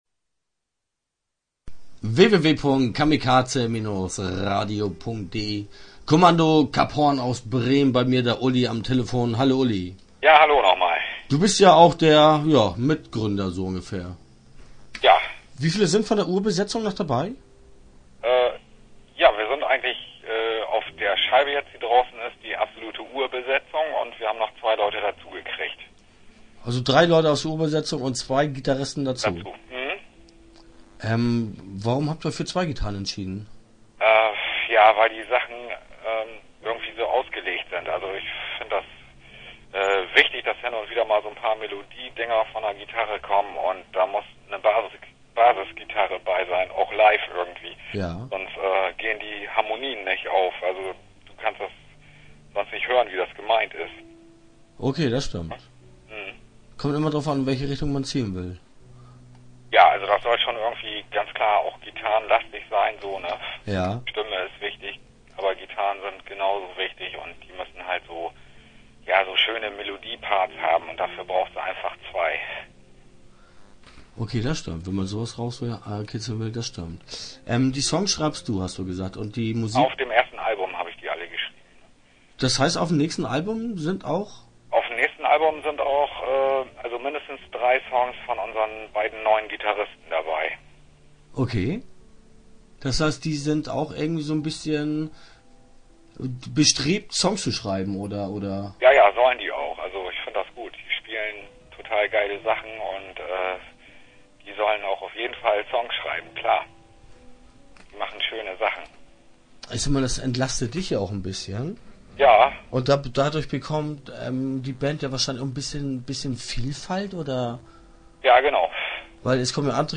Interview Teil 1 (10:27)